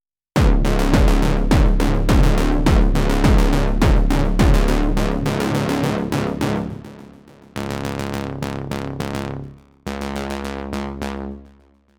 Went a different route using FM here …
after the pause two quick examples representing what I wrote before